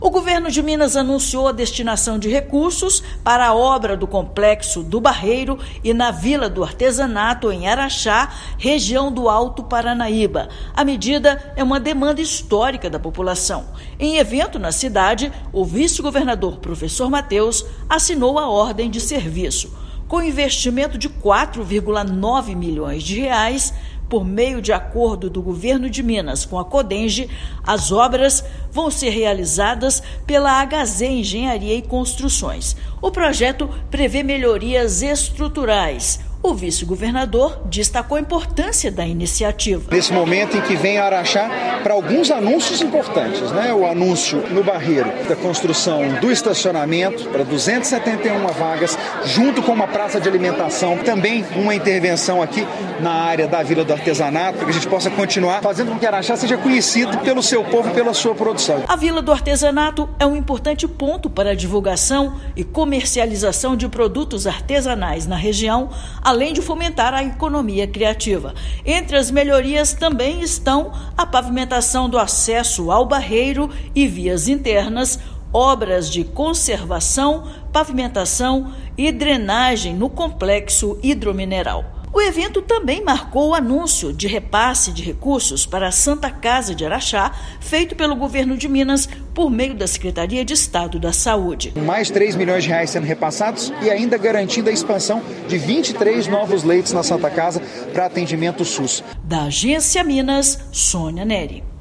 Evento marca ainda a confirmação de repasse para a Santa Casa, para reformas na unidade filantrópica que também atende outras sete cidades da microrregião. Ouça matéria de rádio.